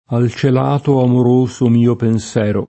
il f$rvido penSL$re] (Parini) — es. di tronc. in poesia (anche nel pl.): Quanti dolci pensier, quanto disio [kU#nti d1l©i penSL$r, kU#nto di@&o] (Dante); Un pensier mesto della madre cara [um penSLHr m$Sto della m#dre k#ra] (Giusti) — in poeti antichi, anche pensero [penS$ro]: Al celato amoroso mio pensero [
al ©el#to amor1So m&o penS$ro] (Petrarca)